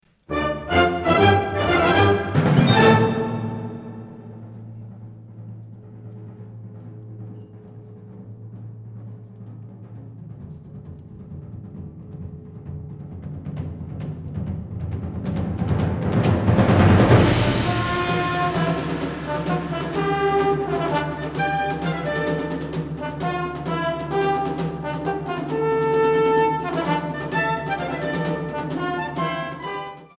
Originale sinfonische Blasmusik aus der neuen Welt und Japan